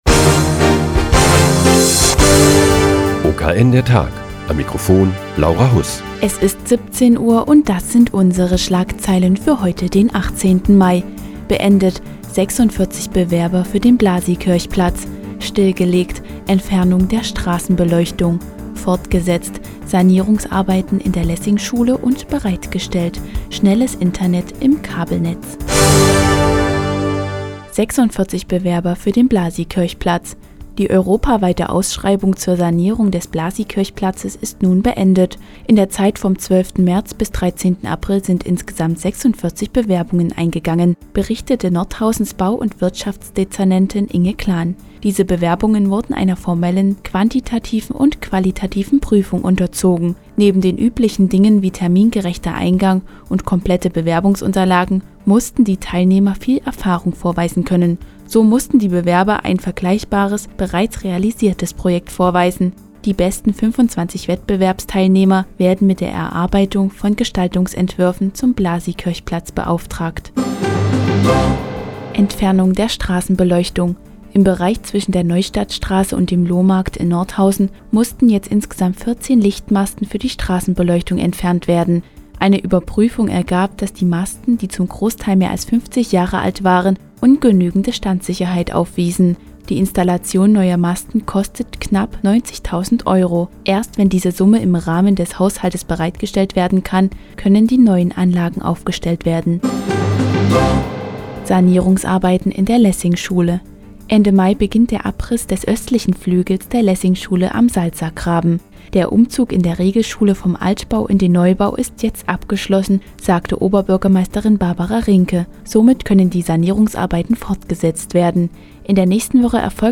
Die tägliche Nachrichtensendung des OKN ist nun auch in der nnz zu hören. Heute geht es um die Bewerbungen für die Sanierung des Blasiikirchplatzes und Sanierungsarbeiten in der Lessingschule.